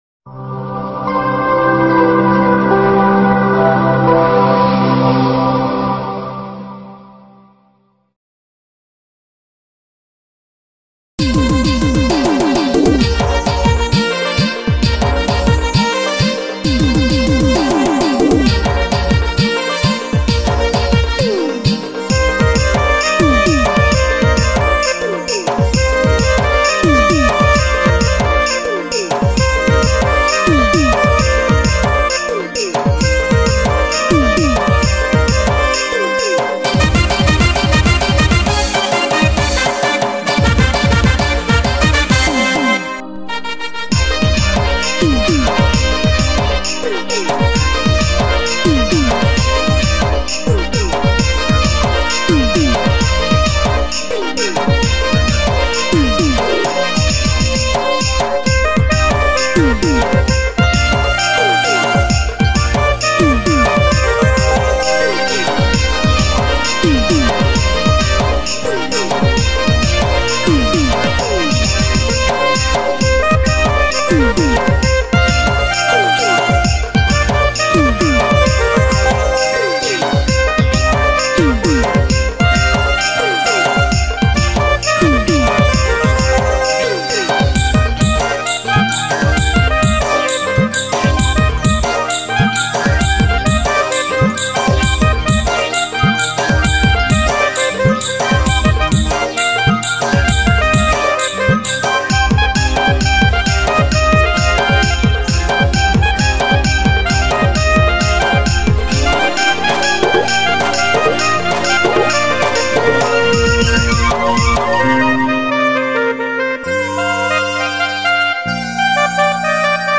Instrumental Music And Rhythm Track Songs Download
Instrumental Music And Rhythm Track